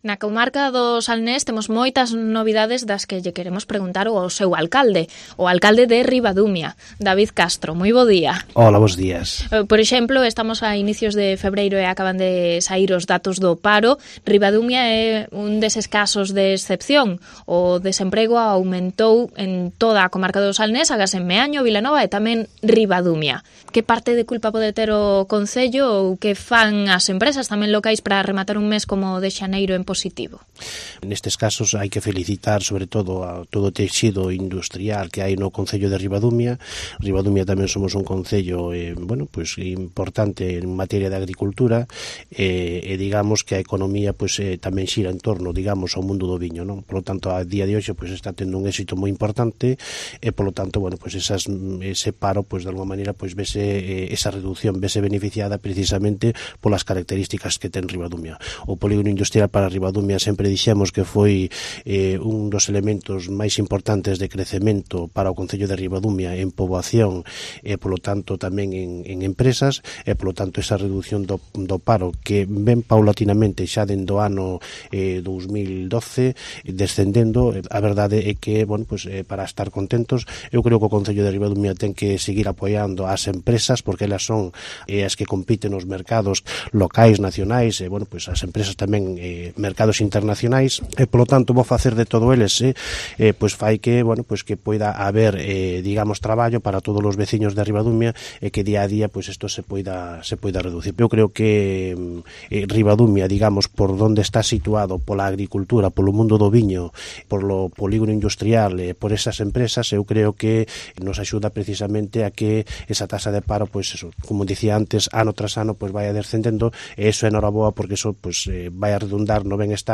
Entrevista en COPE al alcalde de Ribadumia